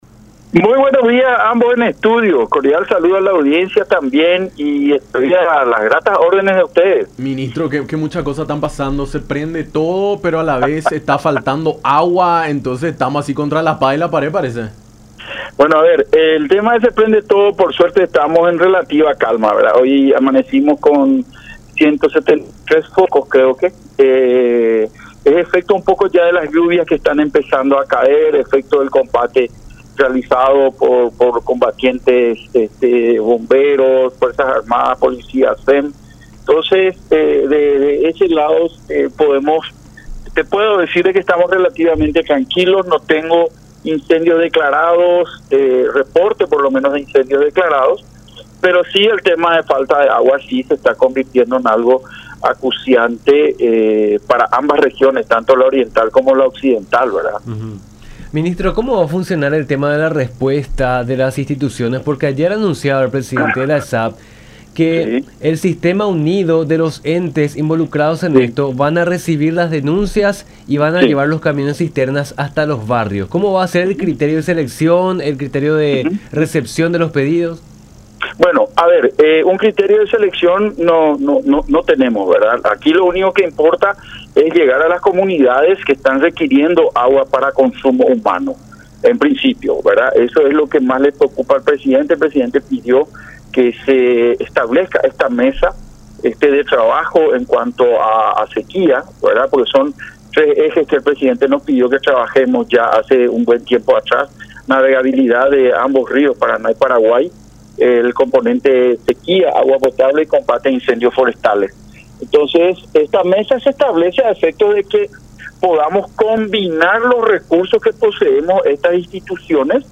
Joaquín Roa, ministro de la Secretaría de Emergencia Nacional (SEN), habló sobre la alianza establecida con la Empresa de Servicios Sanitarios del Paraguay (ESSAP) y el Servicio Nacional de Saneamiento Ambiental (SENASA) para la provisión de agua a comunidades rurales e indígenas del Chaco que sufren ante la falta del líquido, problema que se agrava con la actual sequía.